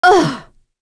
Naila-Vox_Damage_01.wav